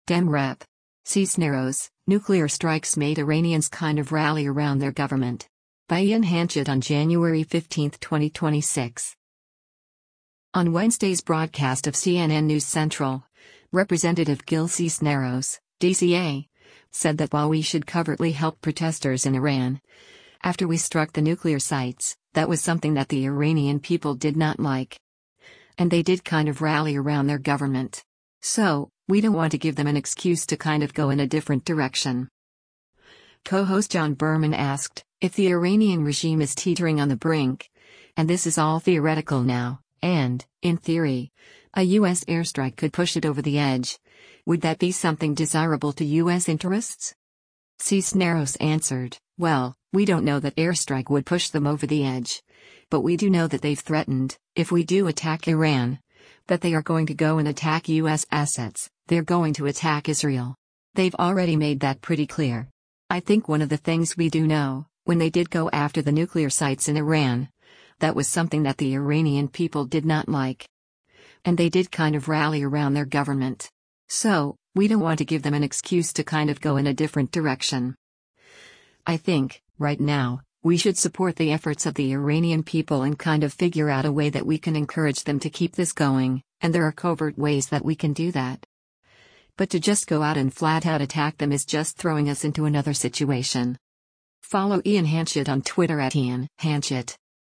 On Wednesday’s broadcast of “CNN News Central,” Rep. Gil Cisneros (D-CA) said that while we should covertly help protesters in Iran, after we struck the nuclear sites, “that was something that the Iranian people did not like. And they did kind of rally around their government. So, we don’t want to give them an excuse to kind of go in a different direction.”